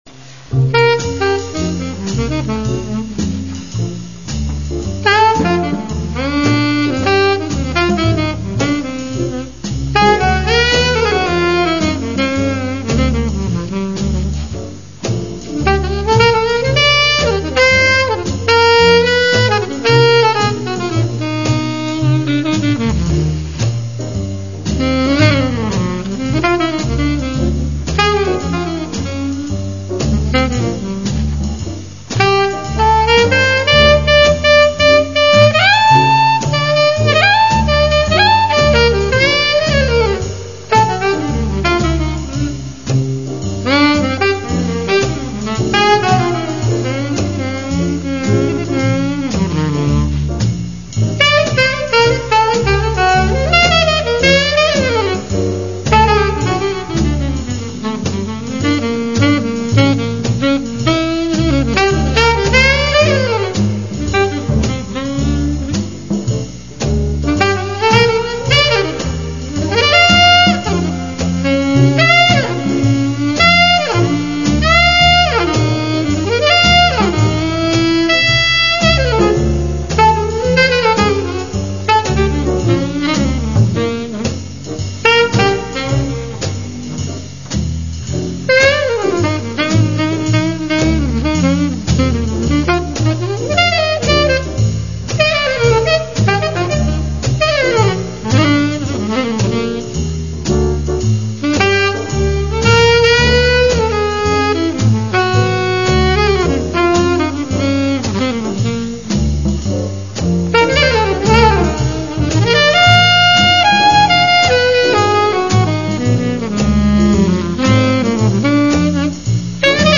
on slower pieces
cool solo